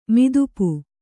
♪ midupu